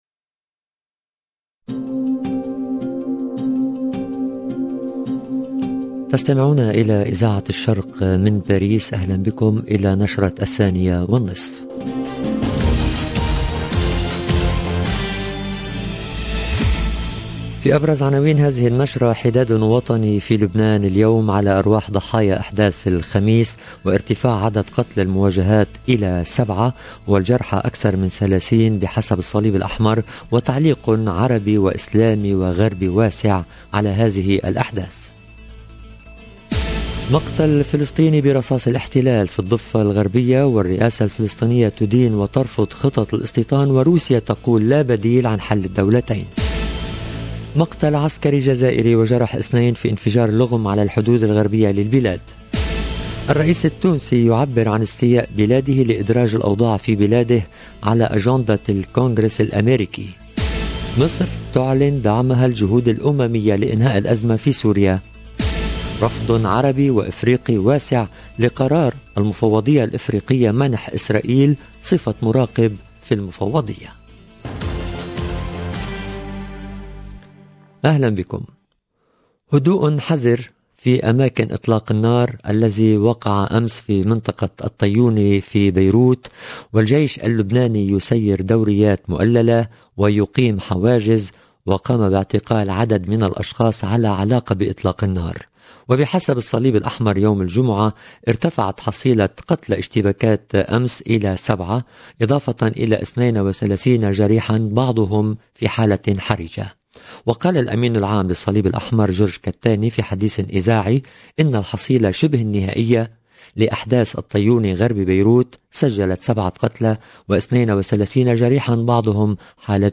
Le journal en langue arabe de la mi-journée du 15/10/21